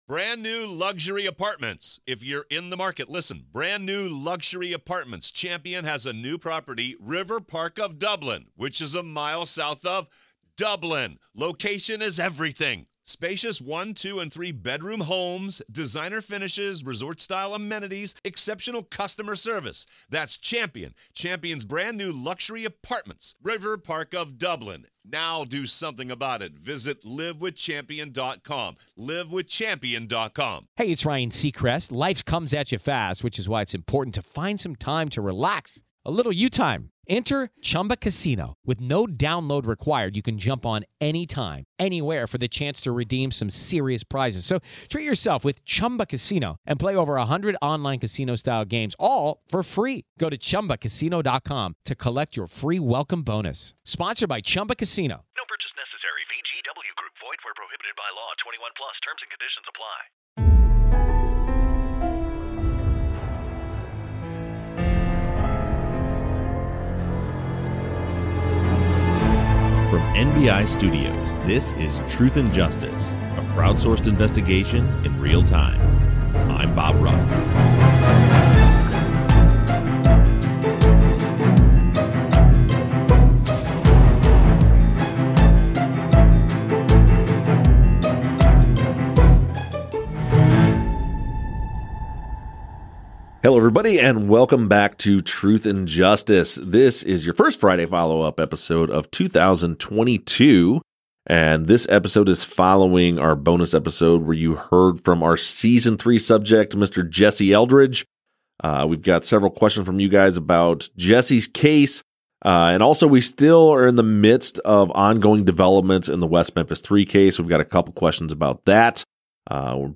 The guys discuss listener questions from social media